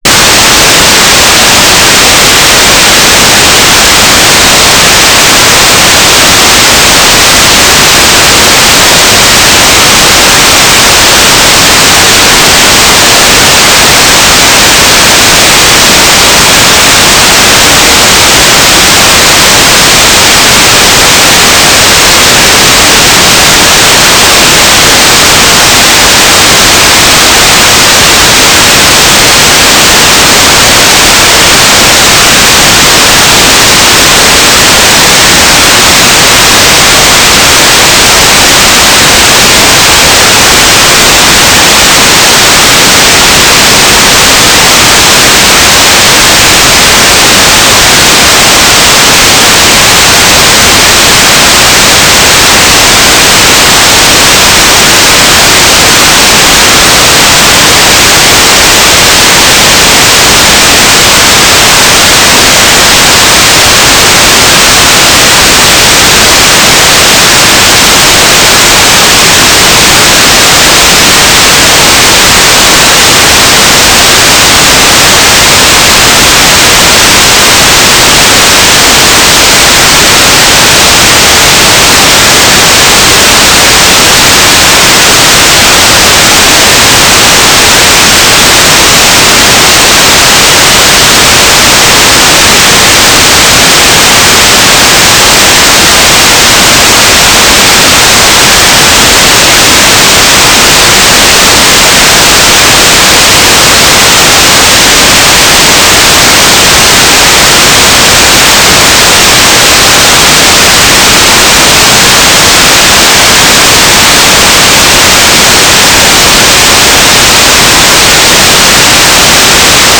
"transmitter_description": "Mode U - GFSK9k6 - AX.25 Beacon and Telemetry (Geoscan framing)",